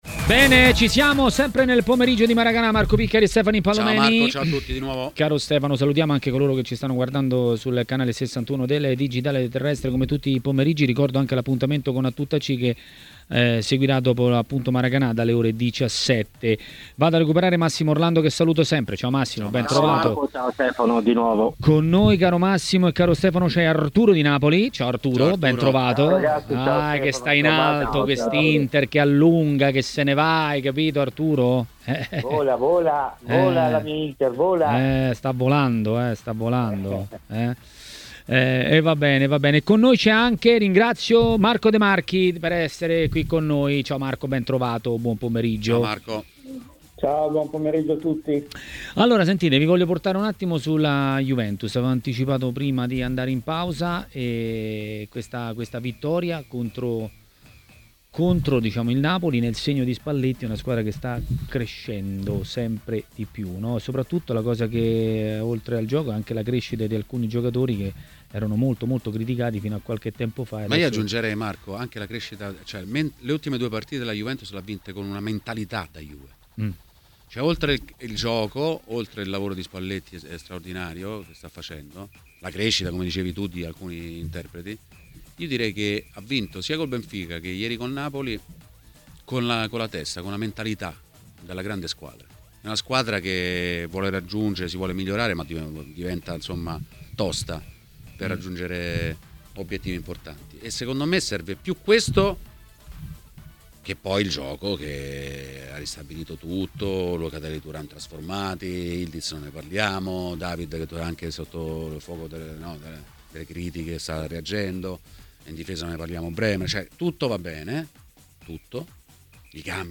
Ospite di TMW Radio, durante Maracanà, è stato l'ex calciatore e agente Marco De Marchi.